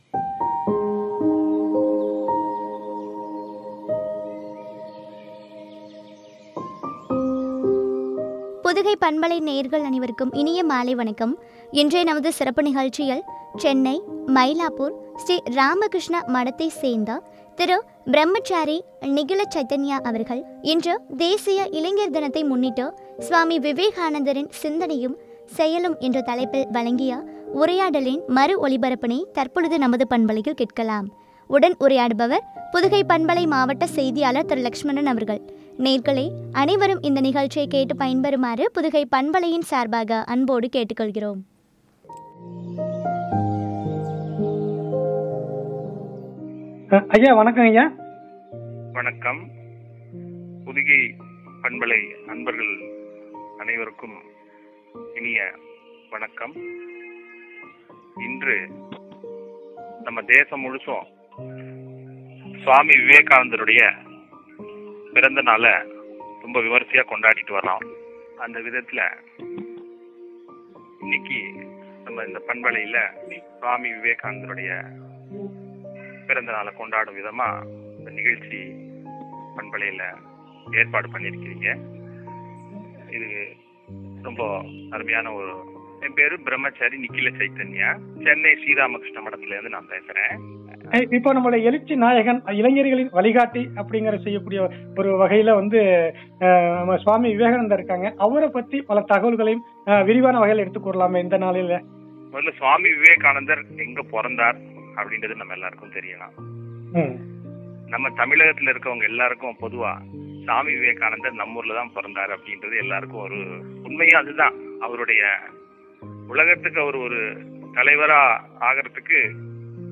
செயலும் என்ற தலைப்பில் வழங்கிய உரை.